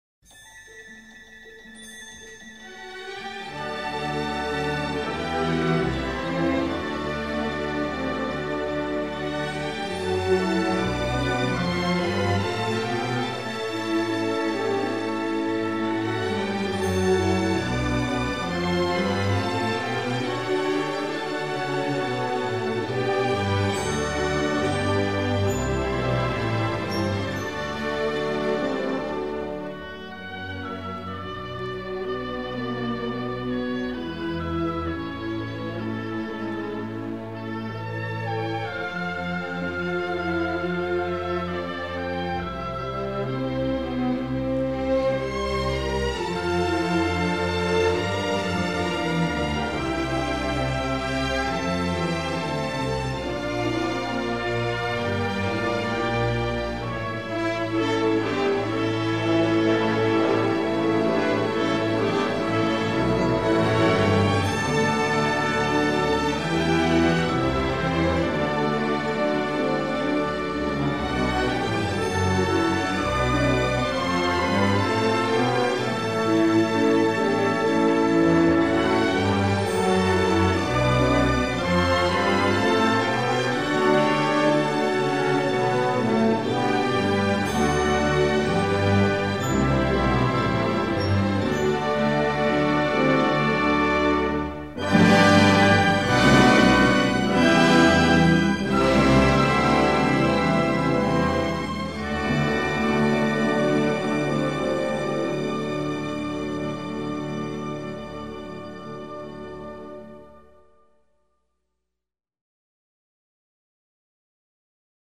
电影音乐采用多主题的交响音乐，其中竞技主题气势如虹、爱情主题炽烈而饱含伤痛。
序曲：